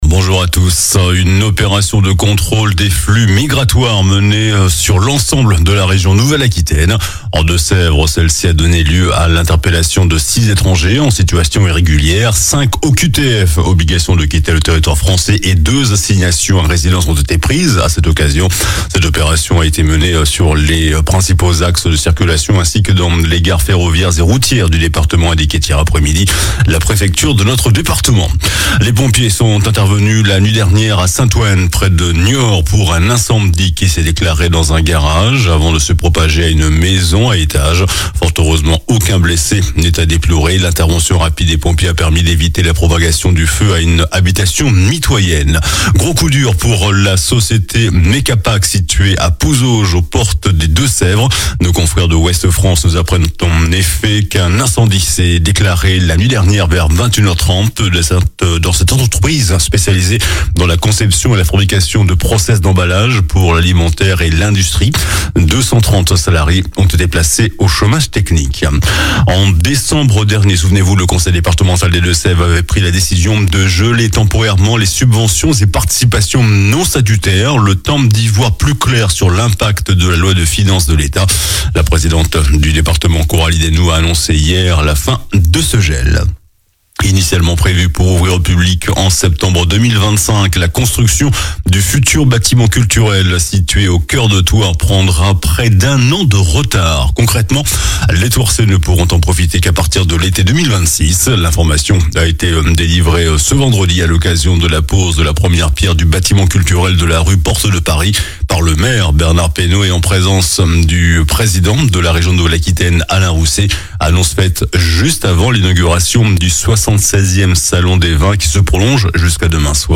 JOURNAL DU SAMEDI 29 MARS